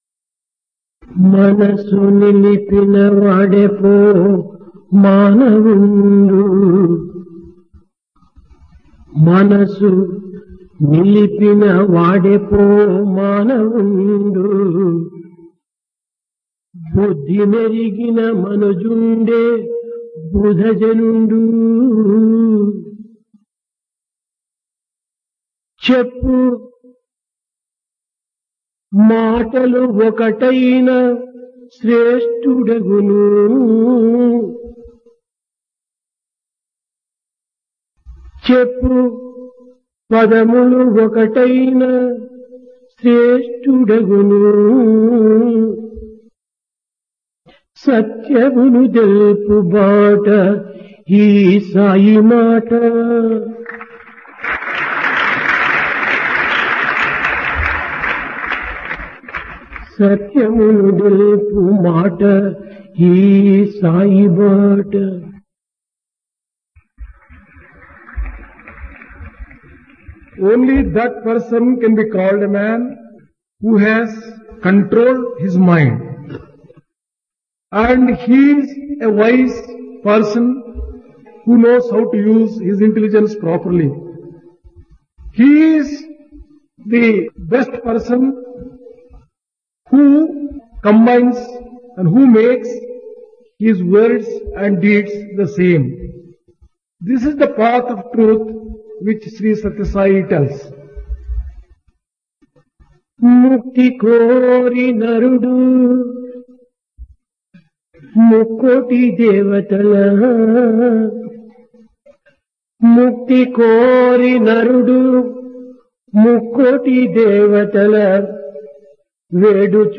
Discourse
Place Prasanthi Nilayam Occasion Dasara